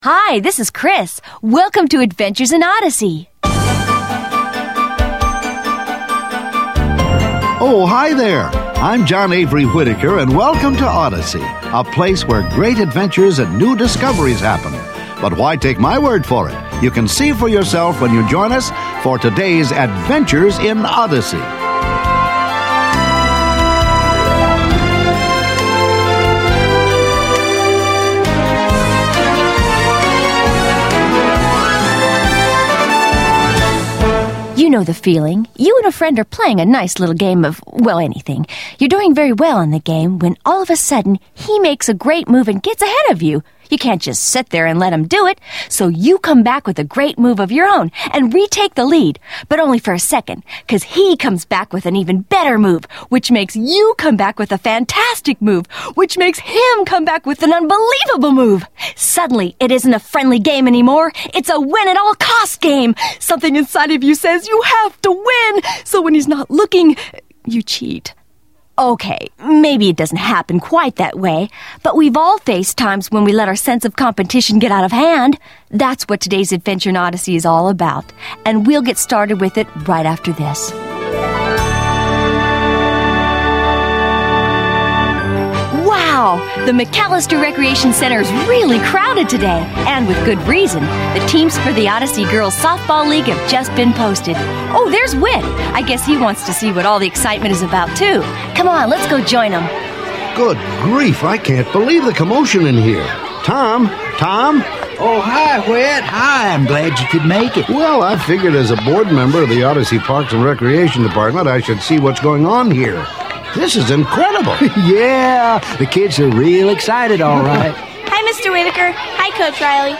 Created for ages 8-12 but enjoyed by the whole family, Adventures in Odyssey presents original audio stories brought to life by actors who make you feel like part of the experience. These fictional, character-building dramas are created by an award-winning team that uses storytelling to teach lasting truths.